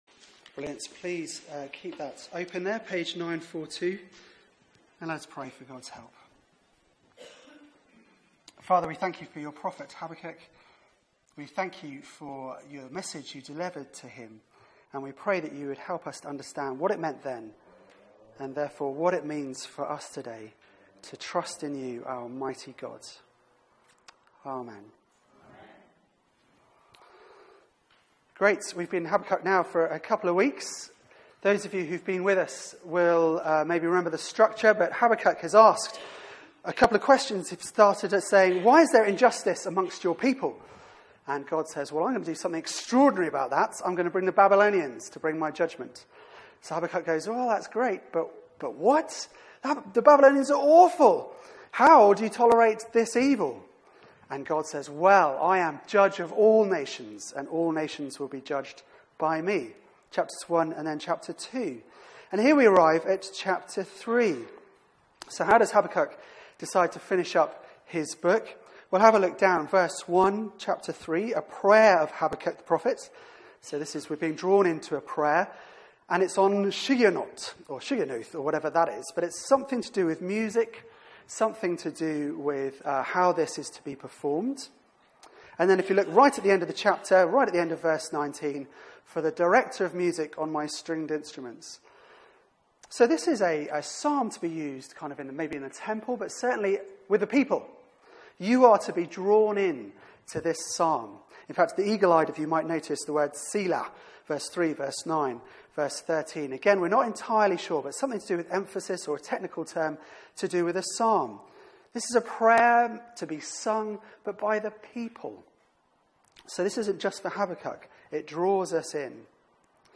Media for 4pm Service on Sun 18th Jun 2017 16:00 Speaker
I will rejoice in the Lord Sermon Search the media library There are recordings here going back several years.